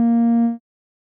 Techmino/media/sample/bass/26.ogg at 387bbfaf695cea8cb9e3cc6f5c95b4bfcd2e2d02